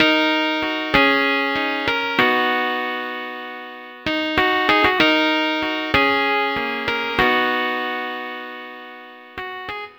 Night Rider - Hard Piano.wav